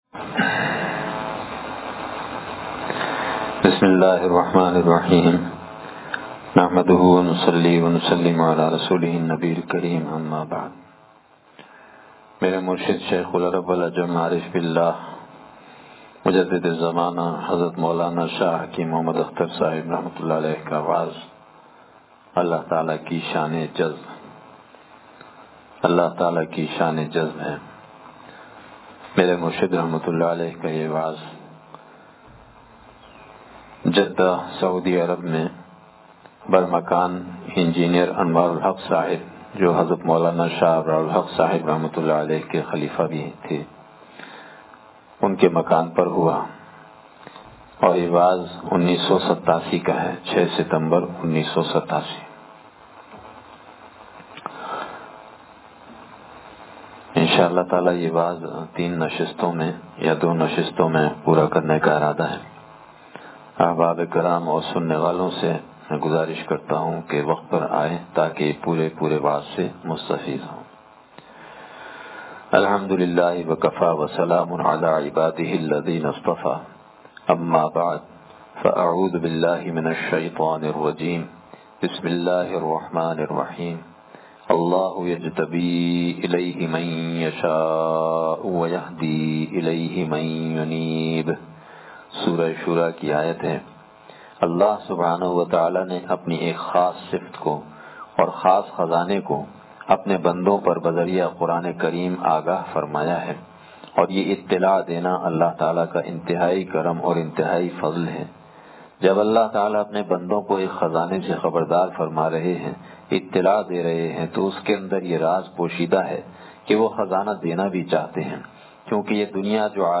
وعظ اللہ تعالی کی شانِ جذ ب (حصہ اوّل)